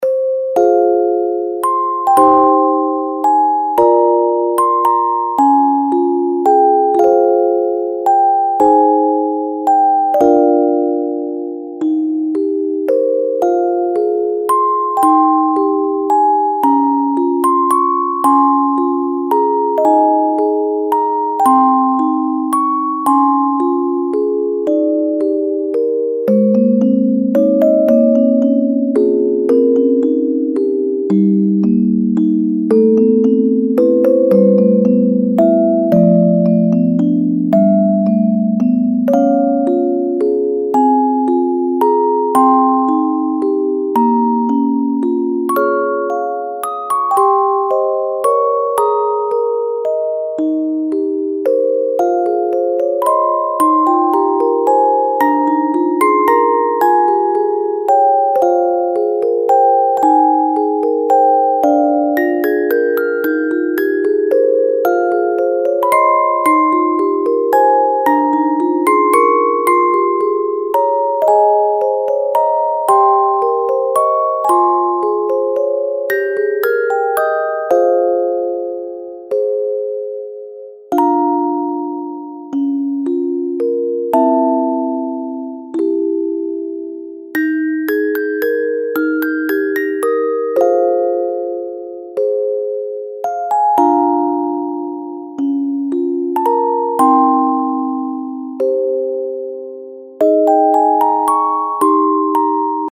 音数の少ない、ゆったりと音を紡いでいくオルゴールBGMです。